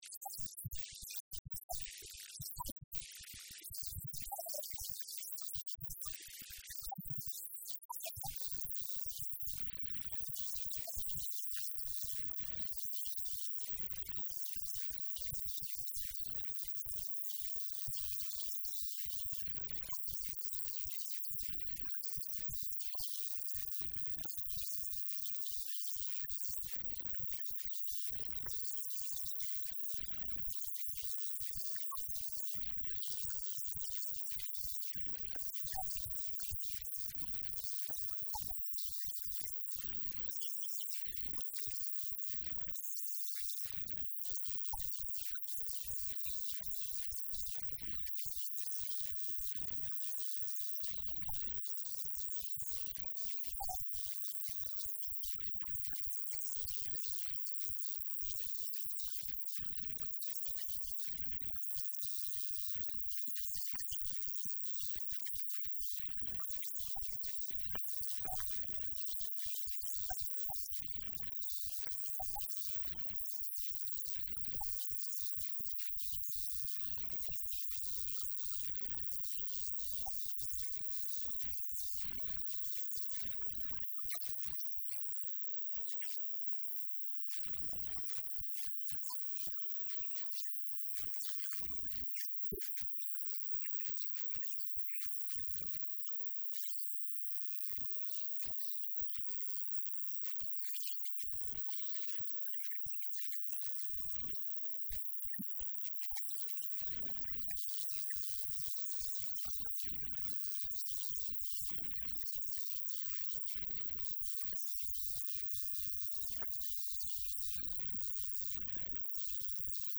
Guddoomiye ku xigeenka Amniga iyo Siyaasadda Maamulka Degmada Balcad ee Gobolka shabeelaha dhexe Abuukar Aadan Codeey oo la hadlay Radio
wareysi-G-ku-xigeenka-Amniga-iyo-siyaasadda-Degmada-Balcad-Abuukar-aadan-codeey.mp3